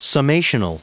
Prononciation du mot summational en anglais (fichier audio)
Vous êtes ici : Cours d'anglais > Outils | Audio/Vidéo > Lire un mot à haute voix > Lire le mot summational